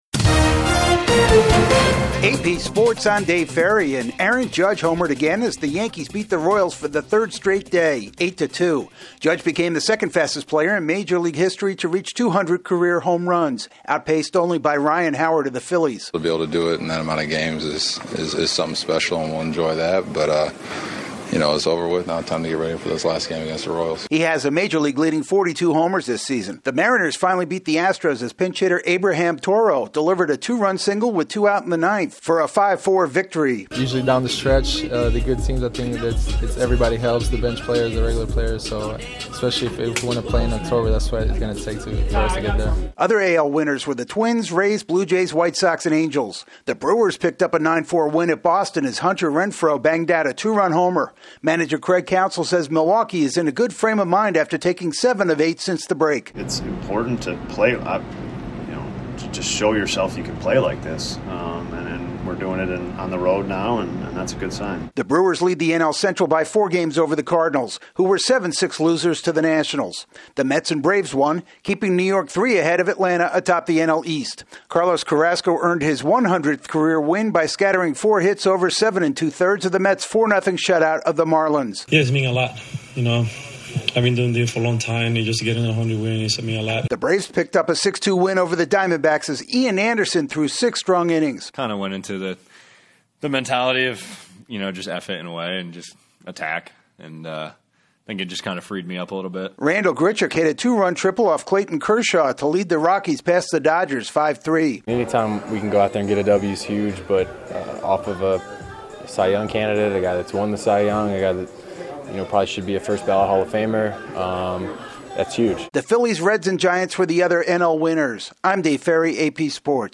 The Yankees' top slugger goes deep again, the M's knock off the Astros, the Brewers pad their division lead, the Braves keep pace with the Mets and the Rockies top the Dodgers. AP correspondent